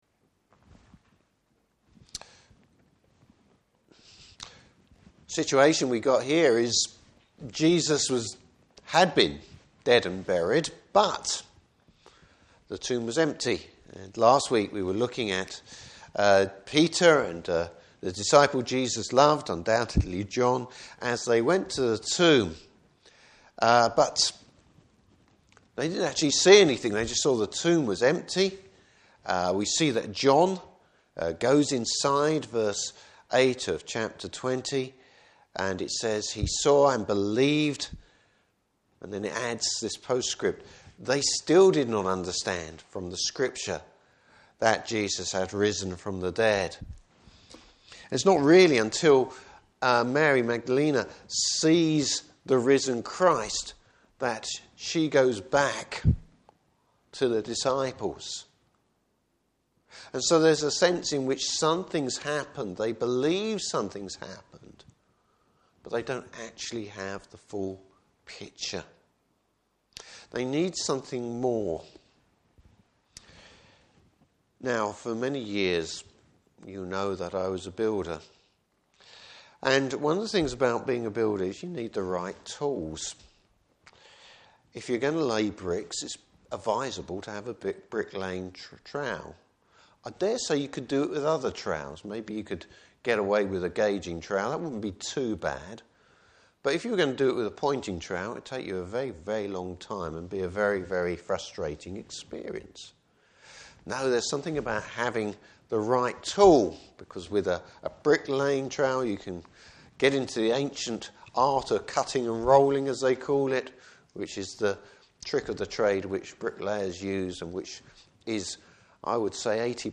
Service Type: Morning Service Bible Text: John 20:19-31.